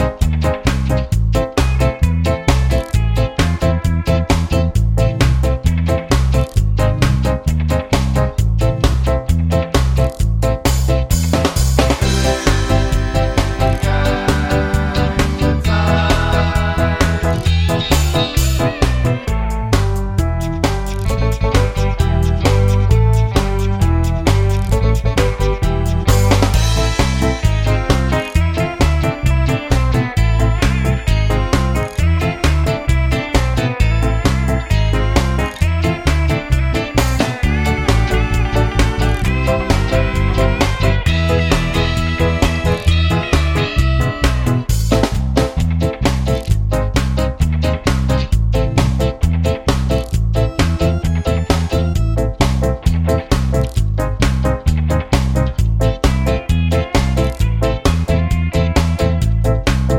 no Backing Vocals Ska 2:44 Buy £1.50